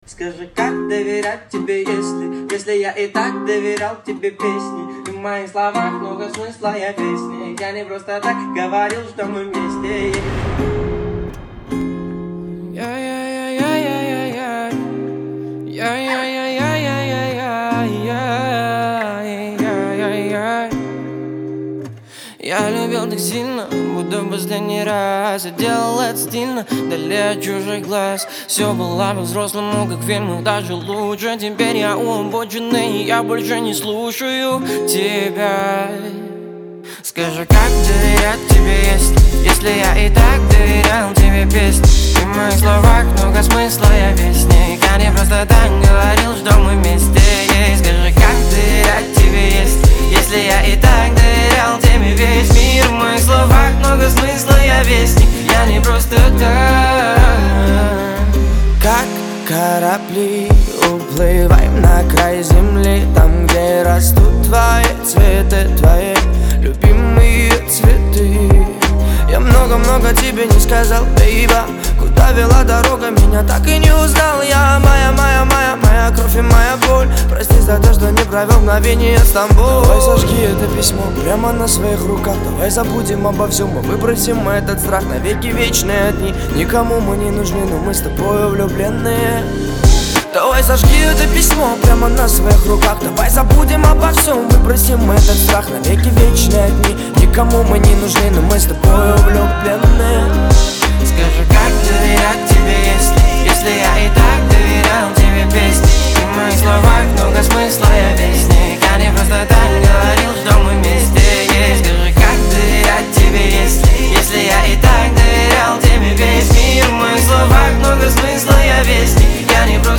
это трек в жанре поп-рэп